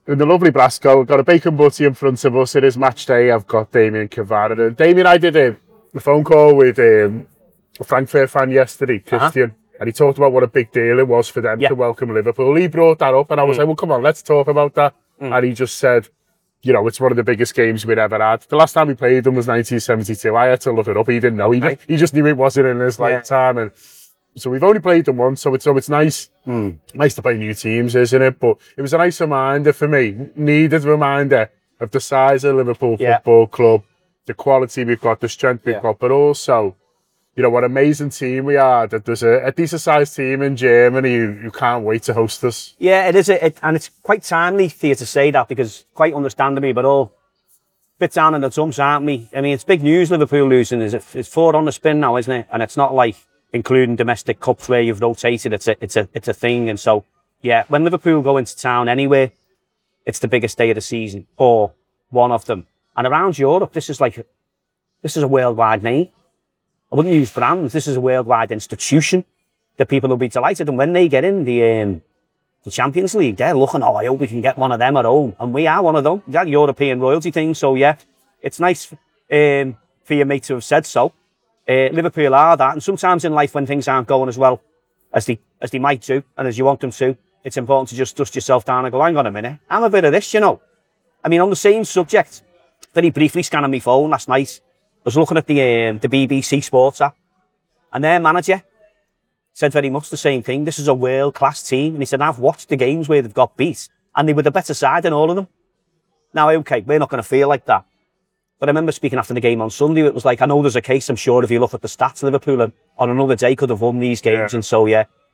A match day morning chat over a Brasco breakfast, discussing the Reds’ upcoming Champions League game against Eintracht Frankfurt at Deutsche Bank Park.